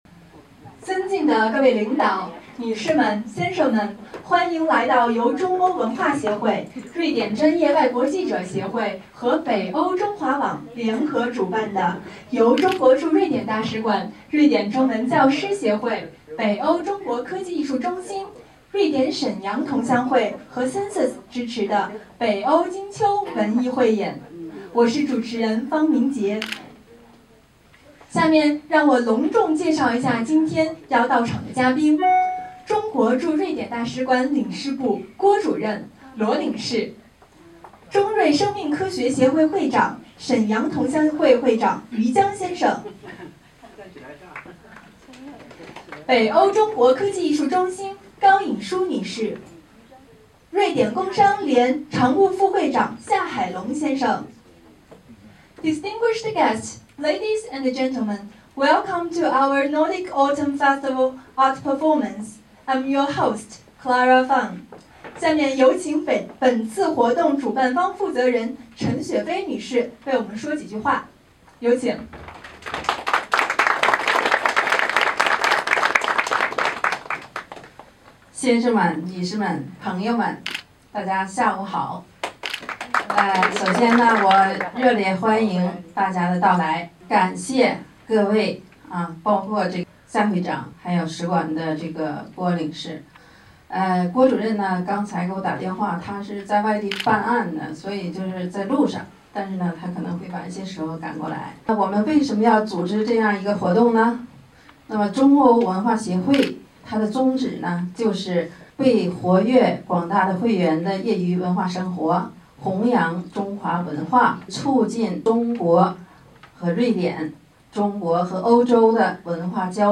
这里是北欧中华广播，今天我们为大家带来了11月5日在斯德哥尔摩举行的北欧金秋文艺汇演现场实况录音。
演出有葫芦丝，钢琴，小提琴，古筝和长笛。也有男女生独唱和诗朗诵。
请听现场实况录音。